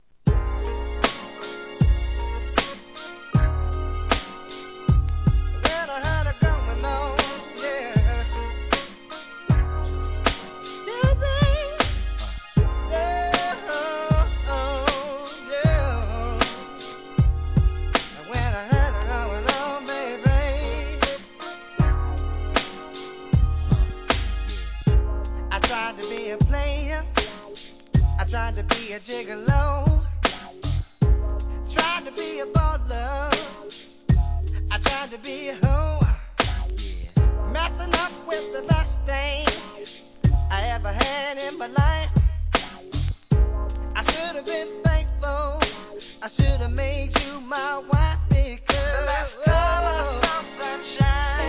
HIP HOP/R&B
1996年、最高のコーラスで王道を行く好R&B!